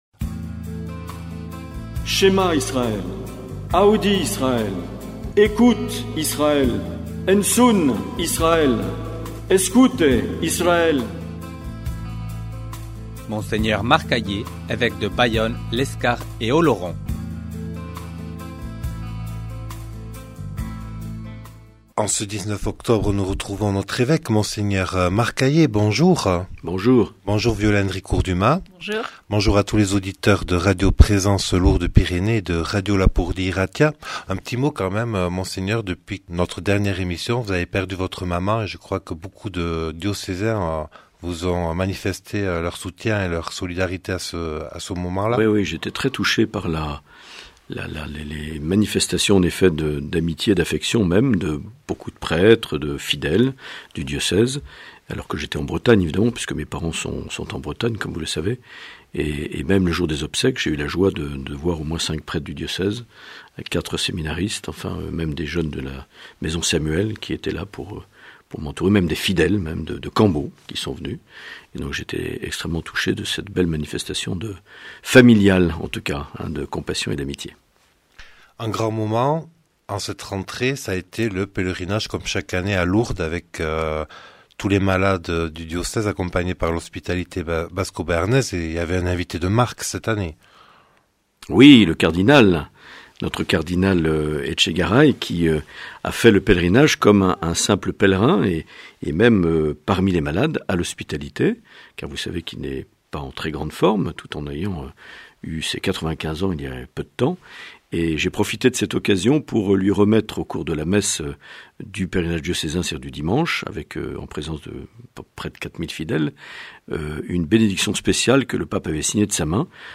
Les entretiens
Une émission présentée par Monseigneur Marc Aillet